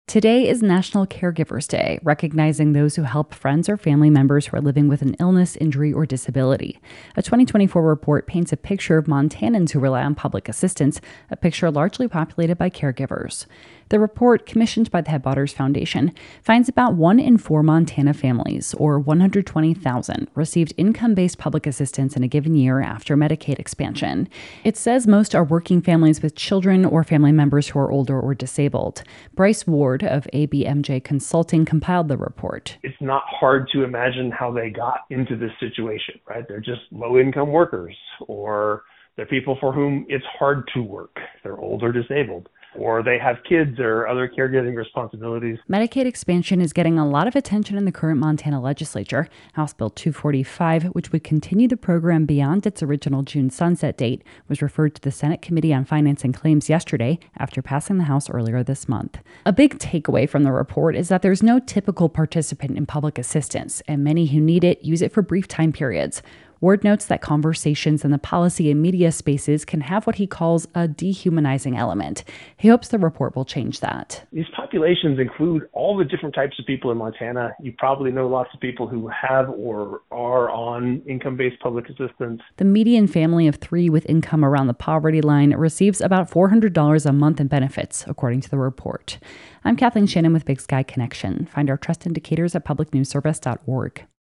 Big Sky Connection - A 2024 report shows about one-quarter of Montana families received public assistance at some point following Medicaid expansion. On this National Caregivers Day (Fri., Feb. 21), their advocates say it's important to highlight that most of those recipients are caregivers.